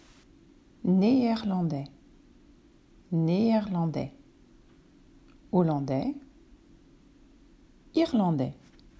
9. Néerlandais: niederländisch (neeärlãdä)